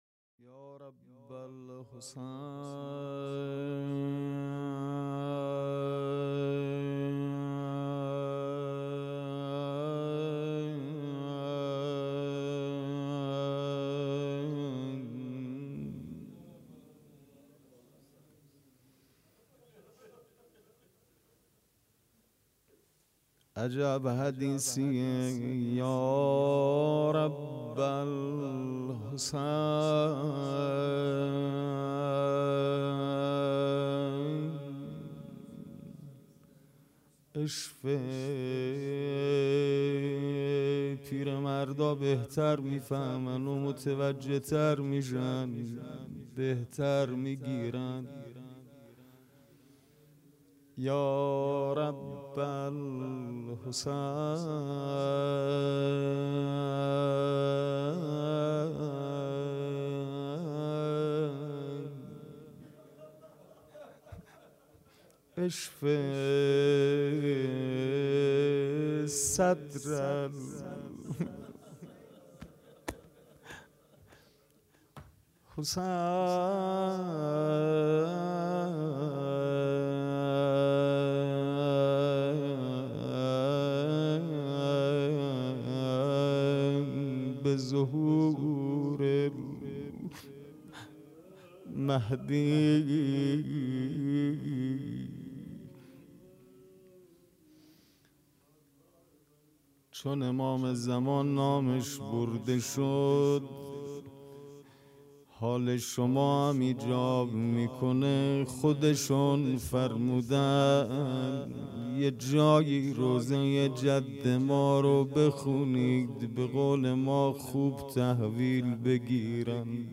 خیمه گاه - هیئت اصحاب الحسین(ع) - شب دوم-روضه
دهه اول محرم الحرام 1441